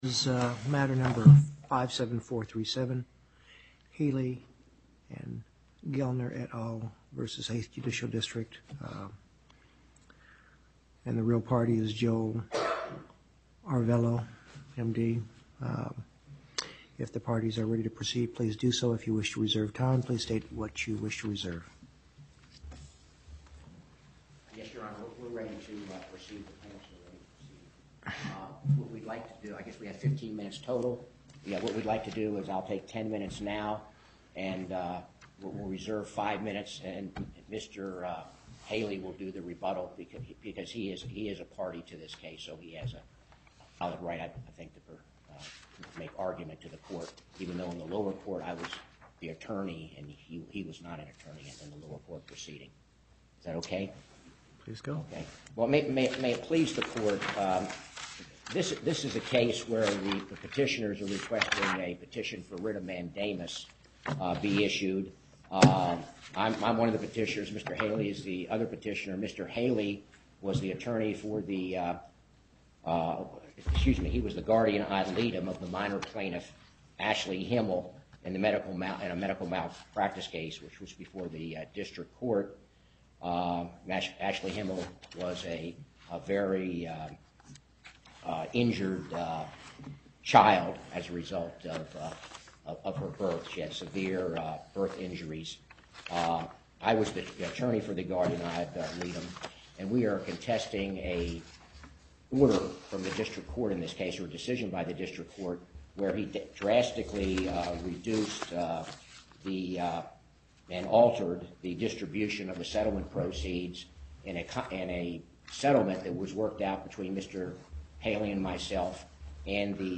Location: Las Vegas Southern Nevada Panel, Justice Douglas Presiding